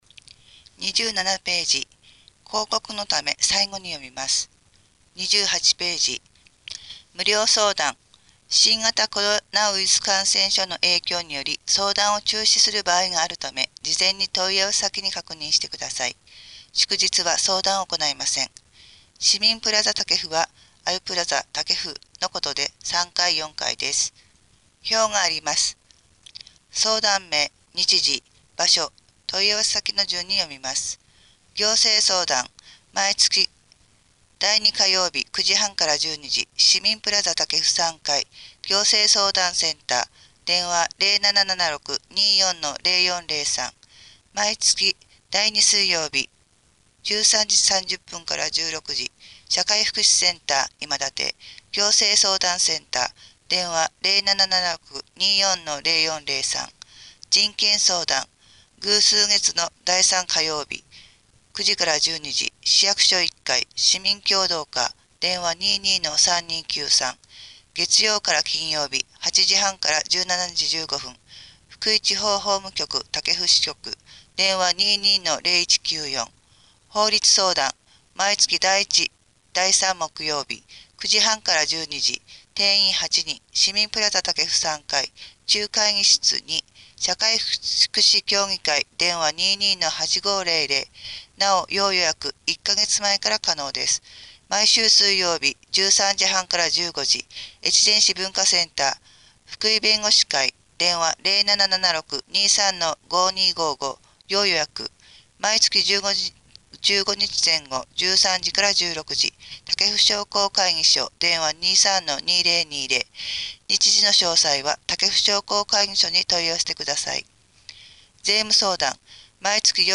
越前市広報１月号（音訳）　令和５年１月１３日発行
※越前市広報の音訳は音訳ボランティア「きくの会」の皆さんのご協力のもと配信しています。